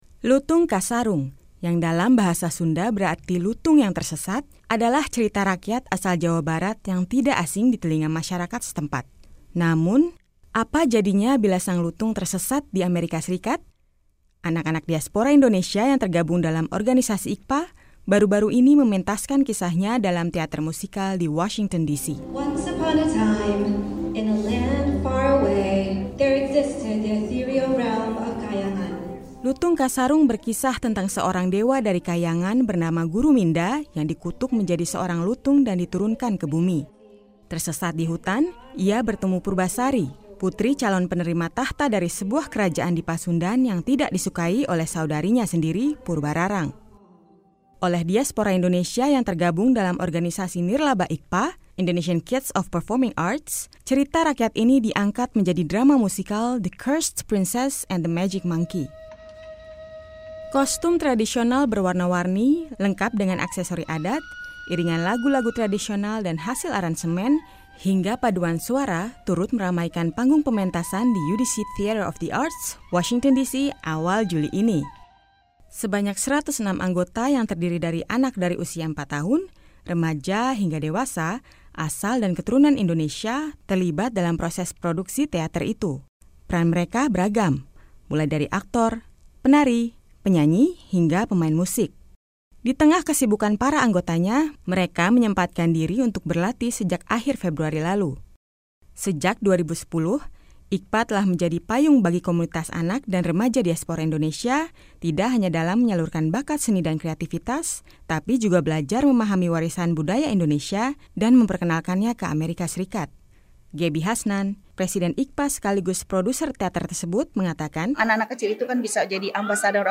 Kostum tradisional berwarna-warni lengkap dengan aksesori adat, iringan lagu-lagu tradisional dan hasil aransemen, hingga paduan suara turut meramaikan panggung pementasan di UDC Theater of the Arts, Washington D.C, awal Juli ini.